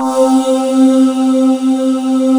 Index of /90_sSampleCDs/USB Soundscan vol.28 - Choir Acoustic & Synth [AKAI] 1CD/Partition D/17-GYRVOC 3D